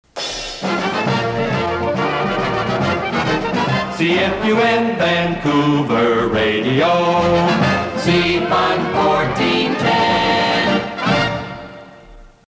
JINGLES FROM EARLY 'FUNLAND' RADIO